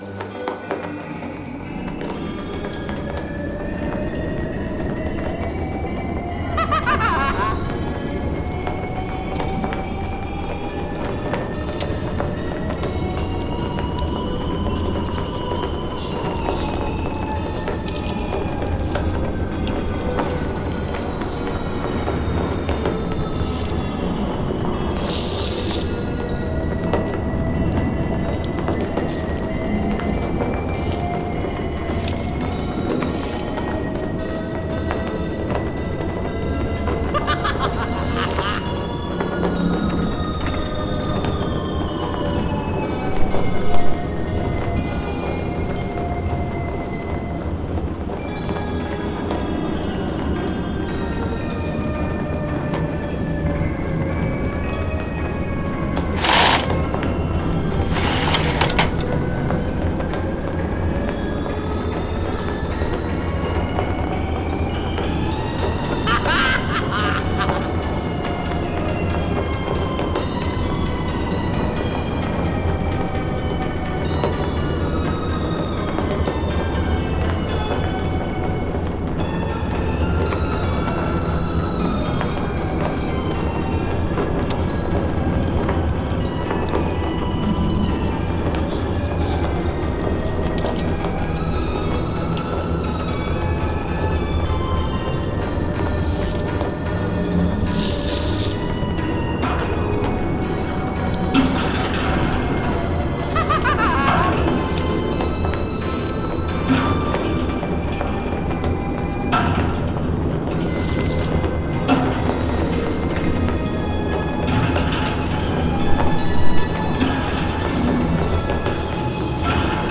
Download creepy carnival Halloween ambience soundscape mp3.
Cursed Carnival Ambience (10 Min MP3)
To celebrate the upcoming Halloween, we are happy to offer you a completely free Halloween ambience soundscape for your spooky videos, podcasts, audio books, apps / games, and social media posts.